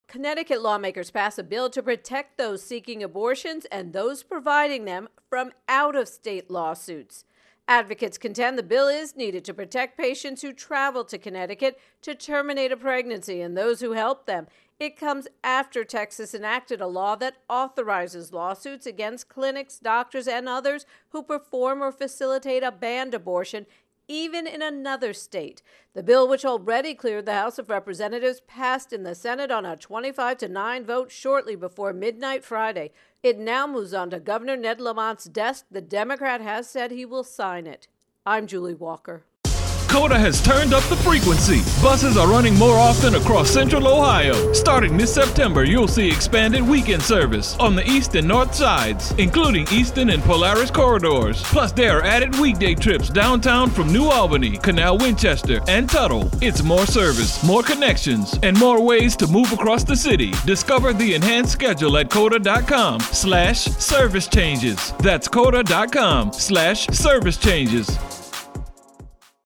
Abortion Connecticut intro and voicer